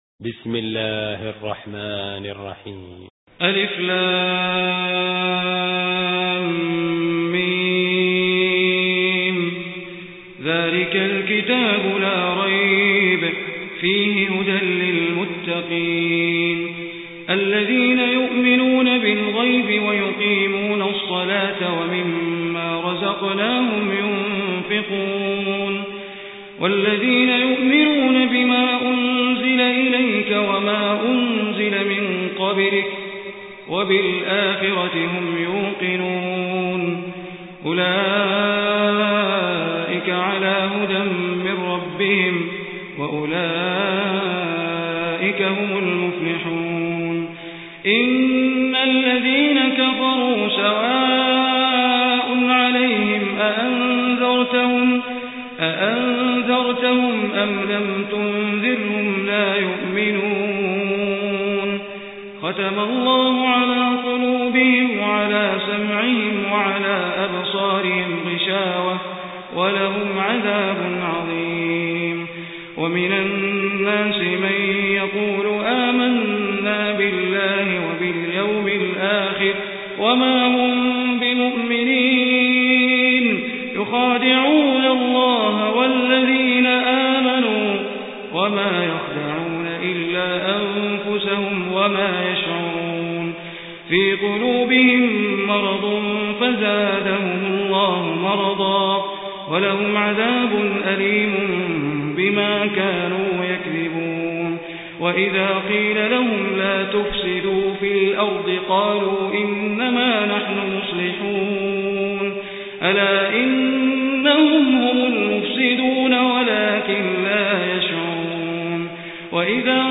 Surah Baqarah Recitation by Sheikh Bandar Baleela
Surah Al Baqarah, listen online mp3 tilawat / recitation in the beautiful voice of Imam e Kaaba Sheikh Bandar Baleela.